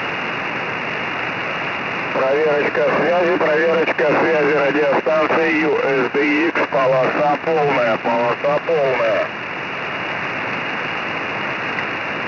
Вот примеры с WEBSdr: